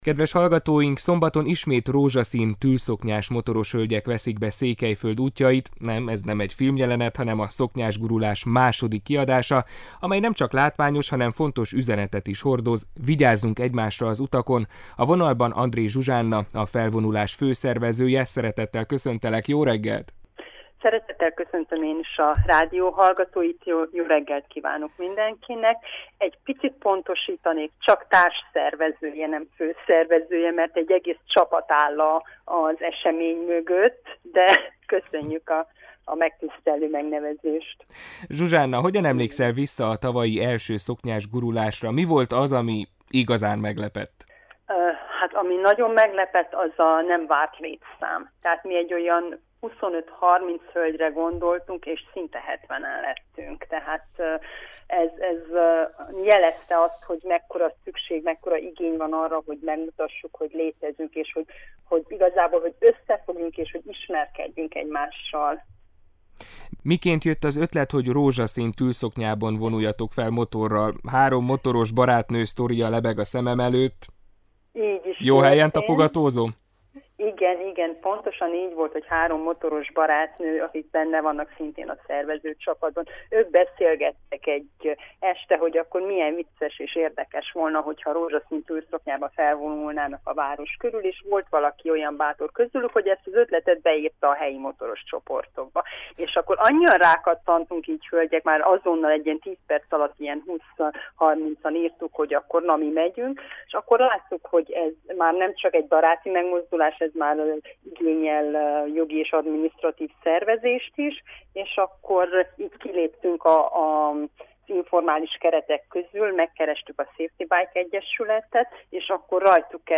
Ismét rózsaszín tüllszoknyás motoros hölgyek veszik be Székelyföld útjait – nem, ez nem egy filmjelenet, hanem a Szoknyás gurulás második kiadása, amely nemcsak látványos, hanem fontos üzenetet is hordoz: vigyázzunk egymásra az utakon! A stúdióban